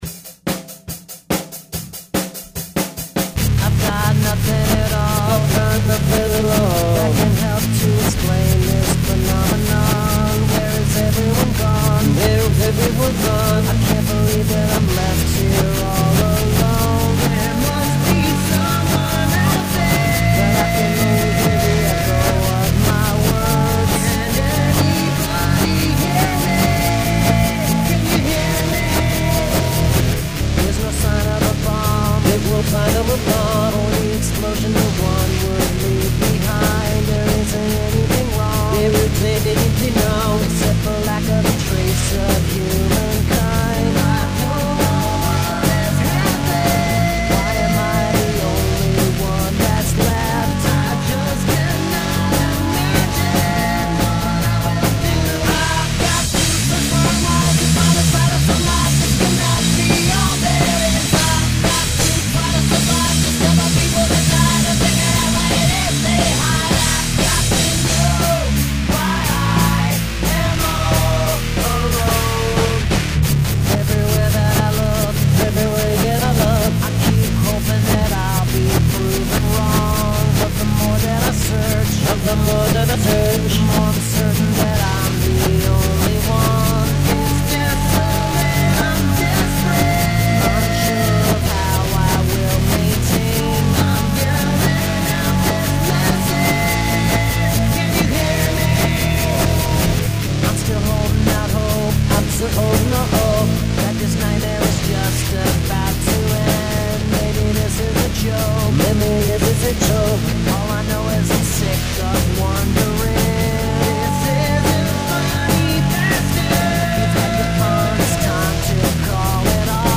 Must include prominent use of backwards recording